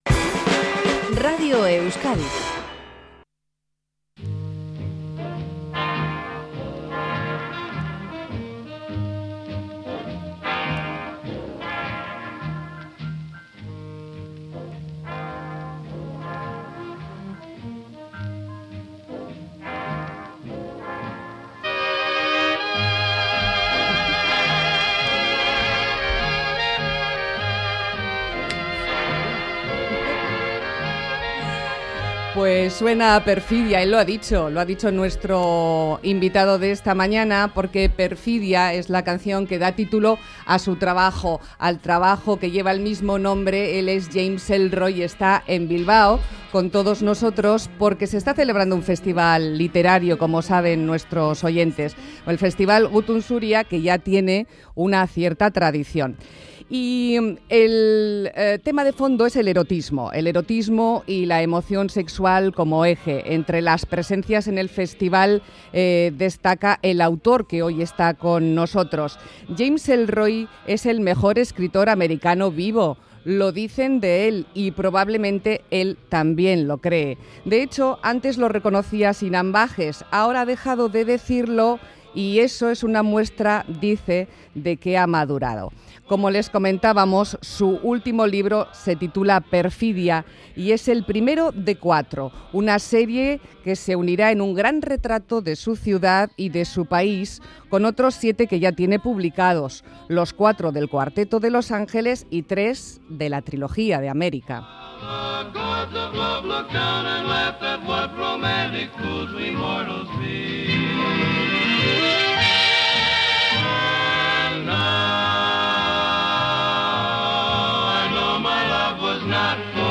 Entrevista: James Ellroy, Perfidia | Más que Palabras Radio Euskadi
Incluso ladra y aúlla. De visita en Bilbao con motivo del festival Gutun Zuria, nos habla de " Perfidia ", su última novela, y de su último reto: una tetralogía.